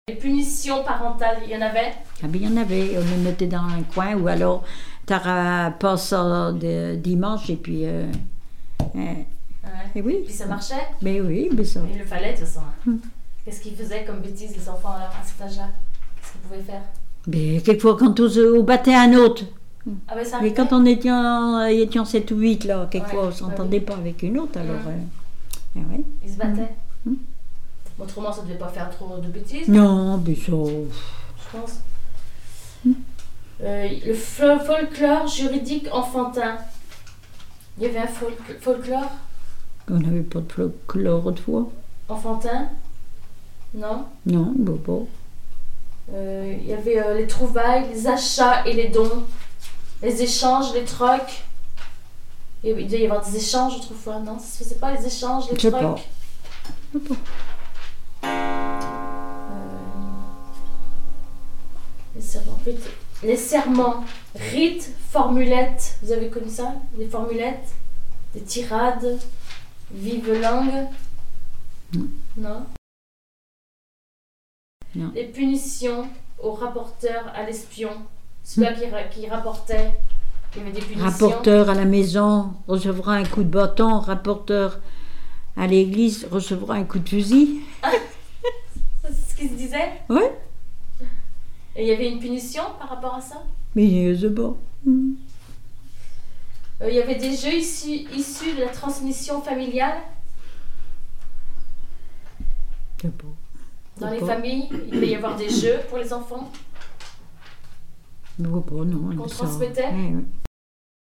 Témoignages sur la vie domestique
Catégorie Témoignage